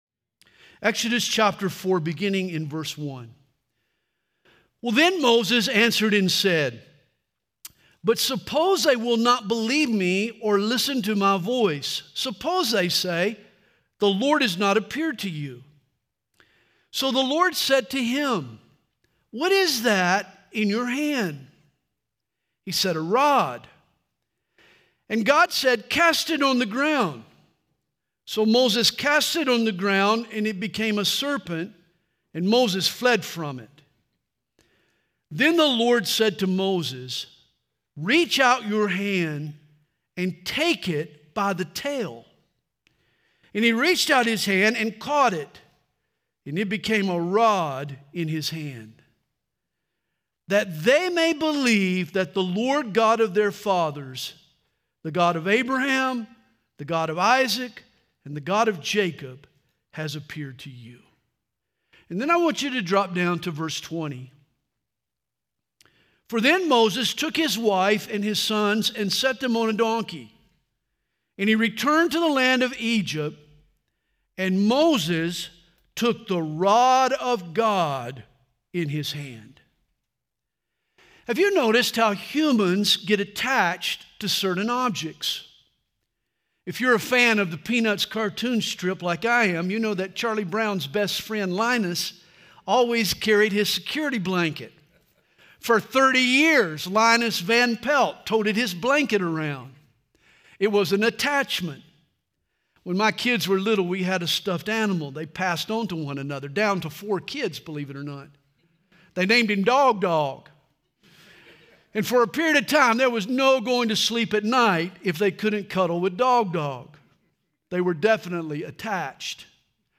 Home » Sermons » The Rod of God
Conference: Worship Conference